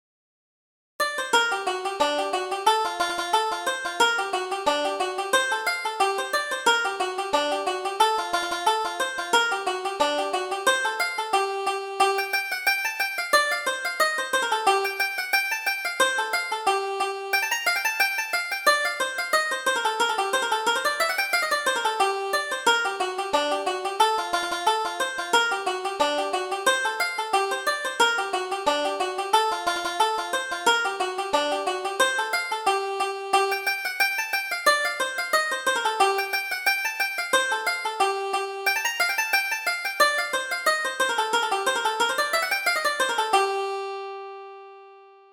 Reel: Miss Crawford